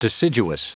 Prononciation du mot deciduous en anglais (fichier audio)
Prononciation du mot : deciduous